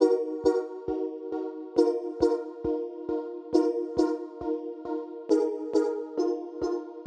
MB - Loop 3 - 68BPM.wav